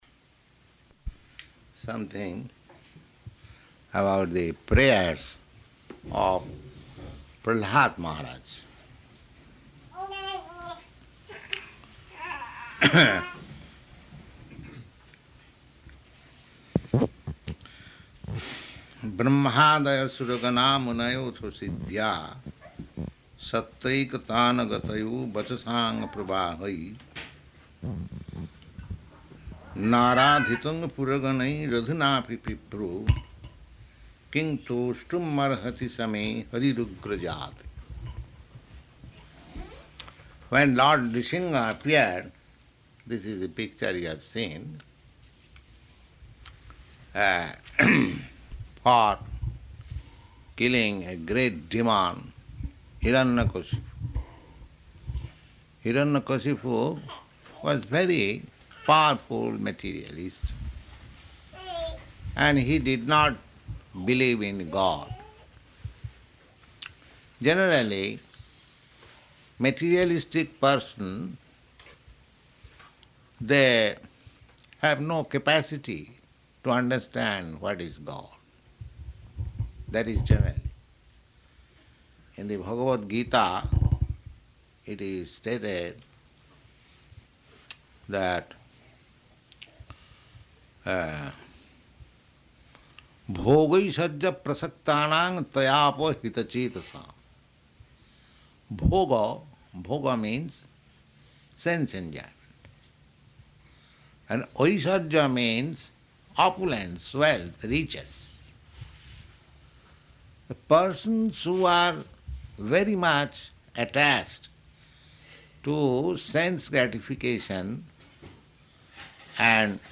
Type: Srimad-Bhagavatam
Location: Seattle